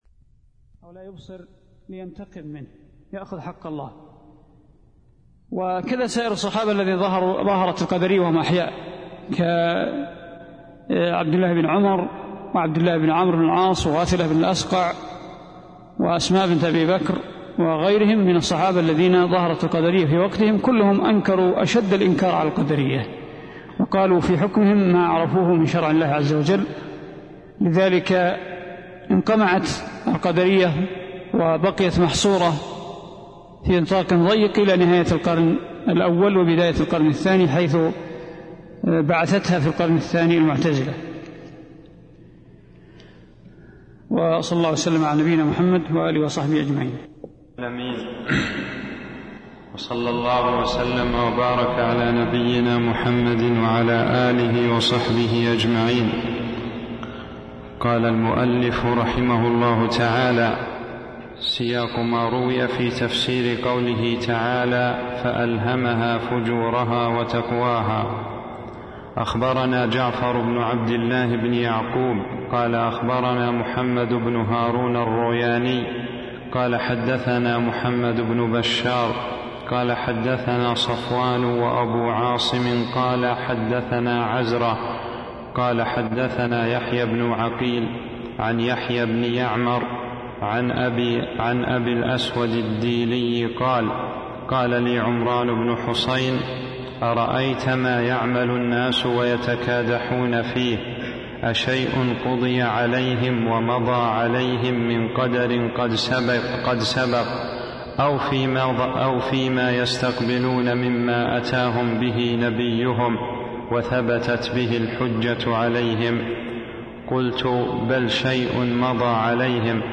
عنوان المادة الدرس (20) شرح أصول إعتقاد أهل السنة والجماعة تاريخ التحميل الأحد 1 يناير 2023 مـ حجم المادة 42.60 ميجا بايت عدد الزيارات 241 زيارة عدد مرات الحفظ 122 مرة إستماع المادة حفظ المادة اضف تعليقك أرسل لصديق